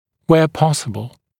[weə ‘pɔsəbl][уэа ‘посэбл]где это возможно